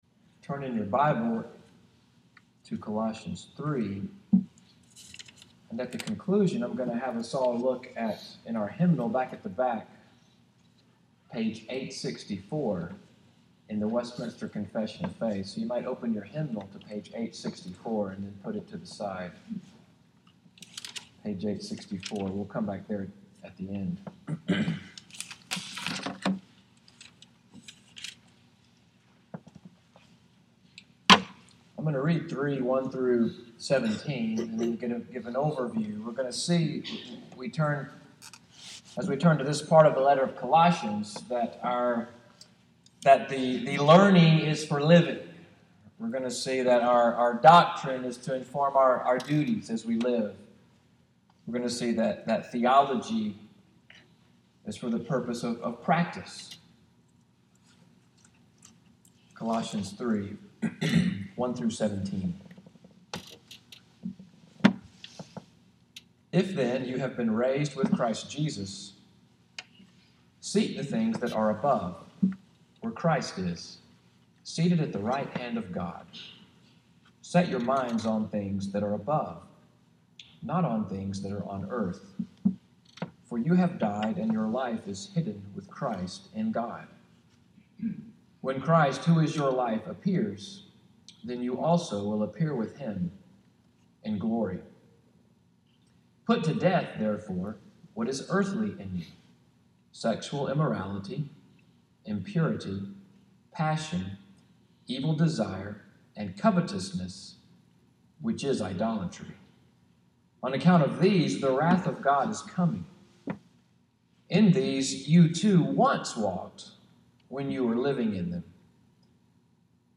EVENING WORSHIP at NCPC, January, 29, 2017, sermon audio, “Spiritual Fashion.”